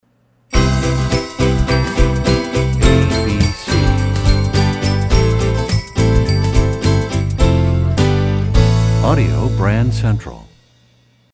MCM Category: Radio Jingles
Genre: Instrumental.